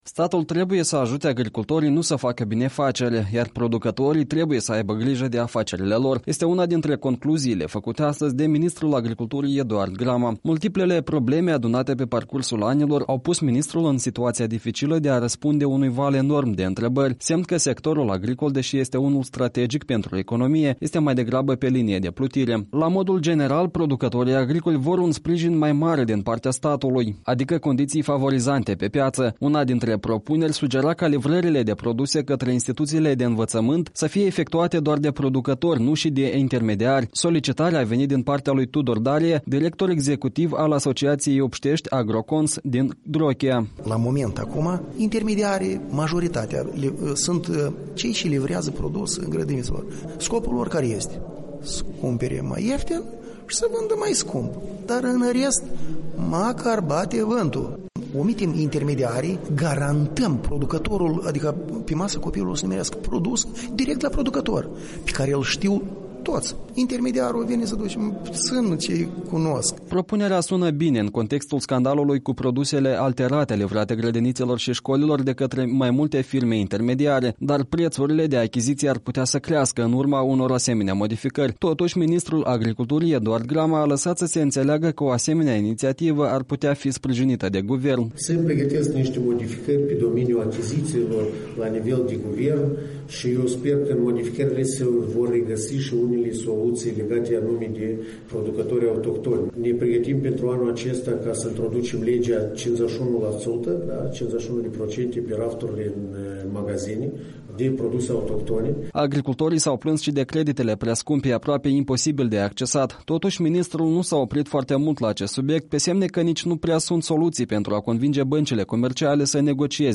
Ministrul a vorbit la adunarea generală a Federației Naționale a Agricultorilor din Moldova.